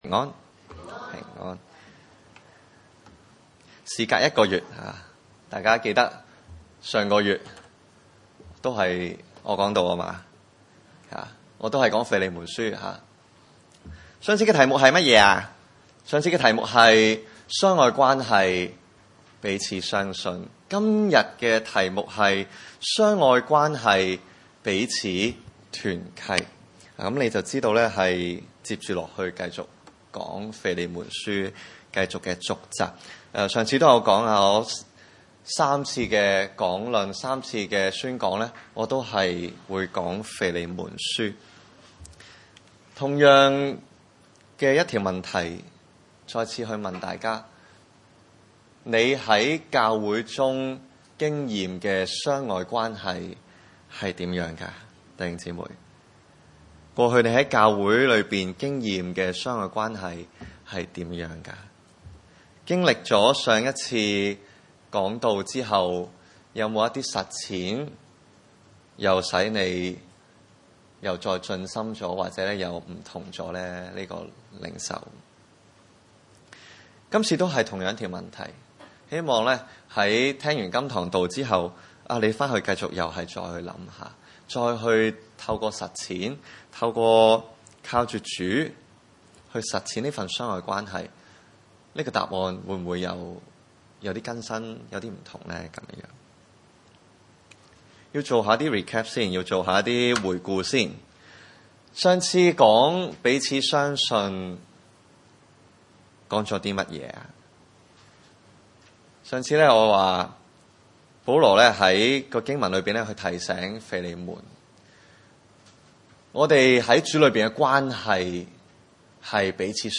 1-25 崇拜類別: 主日午堂崇拜 1 為基督耶穌被囚的保羅，同兄弟提摩太寫信給我們所親愛的同工腓利門， 2 和妹子亞腓亞並與我們同當兵的亞基布，以及在你家的教會。